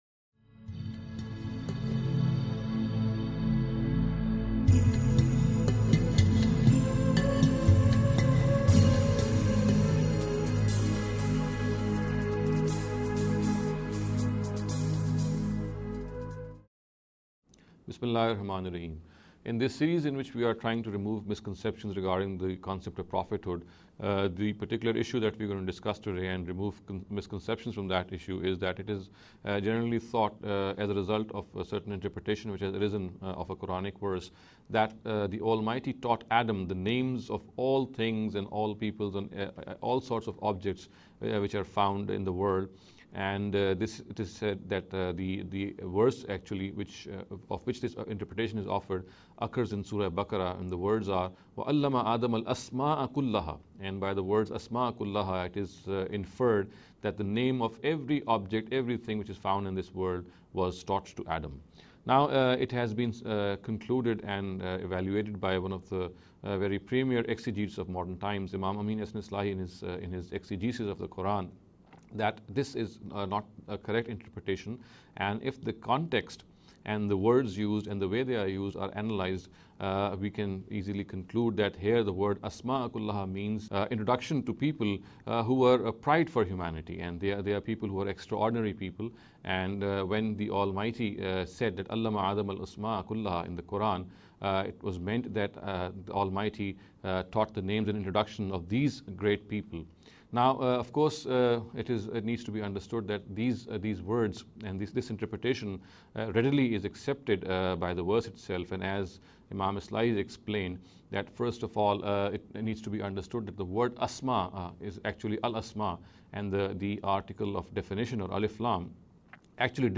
In this series of short talks